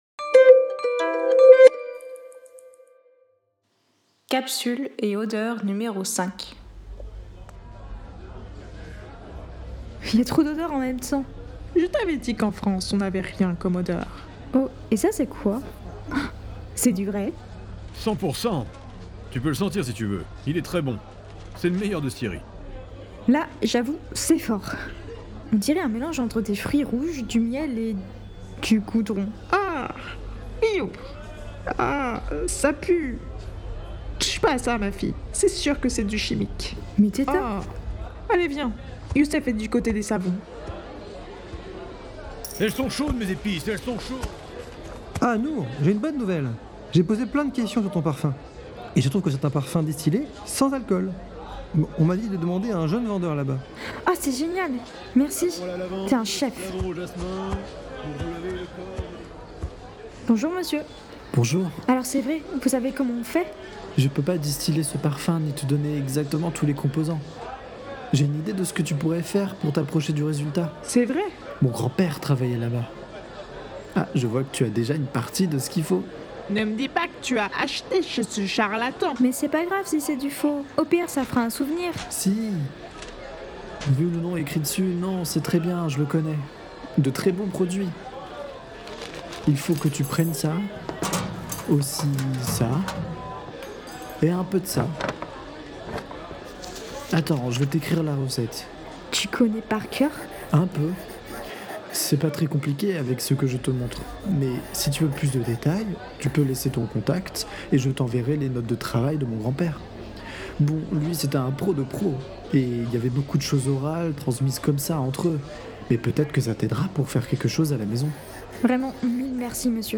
Cette fiction sonore ludique et immersive a été proposée dans le cadre du Pôle Fictions Sonores de PodRennes 2025.